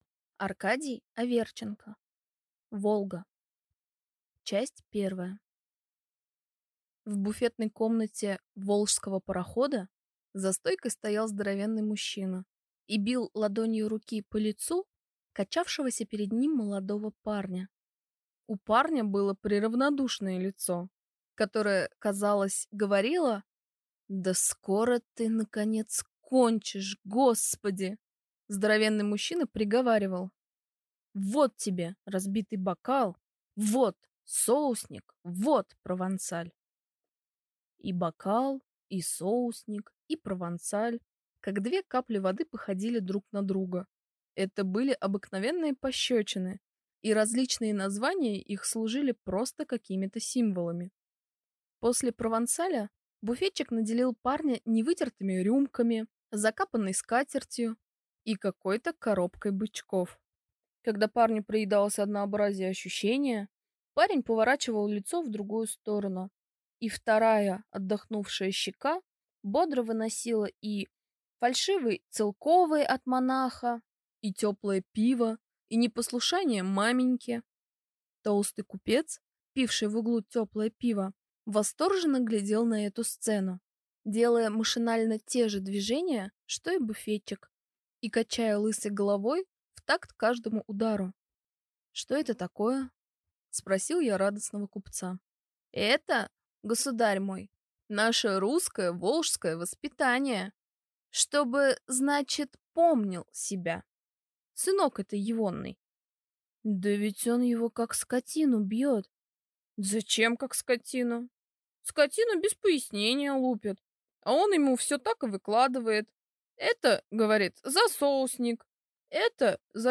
Аудиокнига Волга | Библиотека аудиокниг